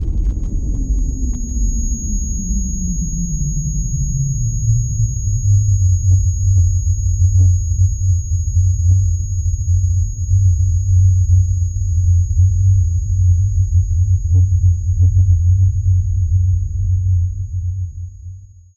кино эффекты звуки скачать, слушать онлайн ✔в хорошем качестве